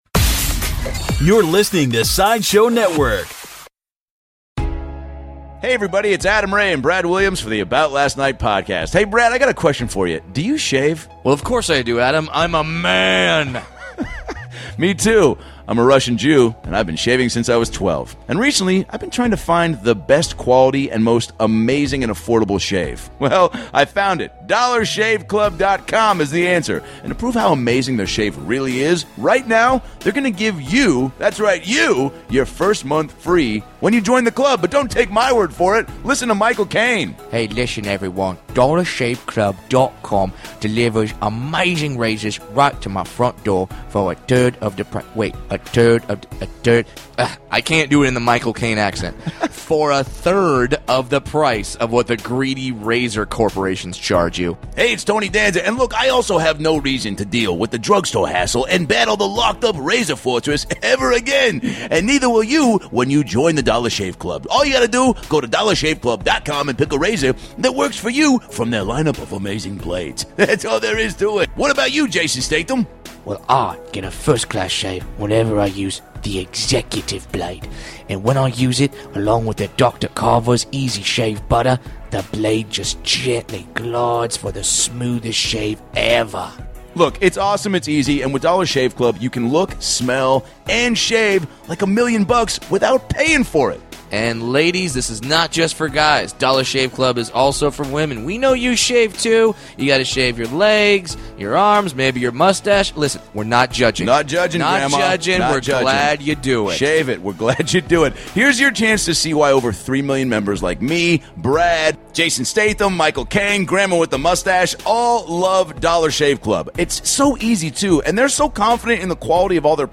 Chris Spencer has written for The Emmys, The ESPYS, House Husbands of Hollywood, In Living Color, and is one of the best comics working today! From hanging with OBAMA, playing basketball with Jordan, advice from Bill Cosby, and how he learned to hone his performer side, it's a hilarious conversation with stories you won't hear anywhere else!